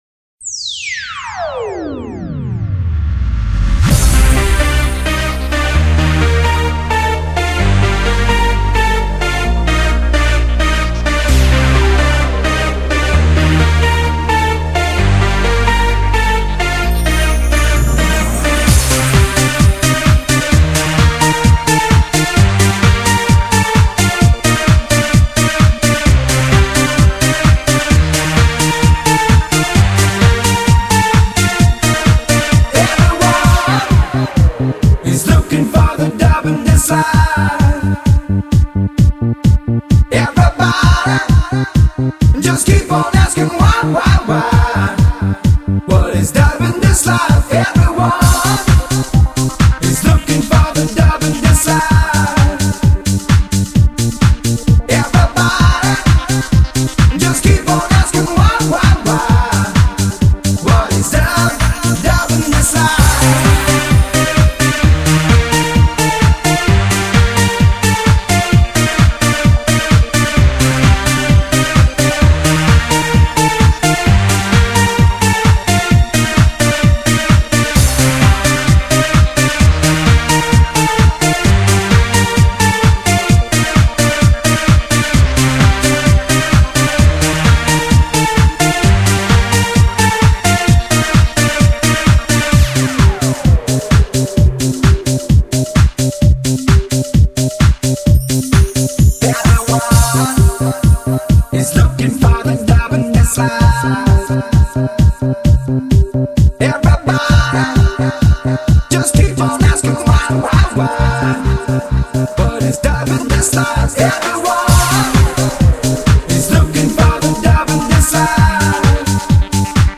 It's surely a part of electronic pop history.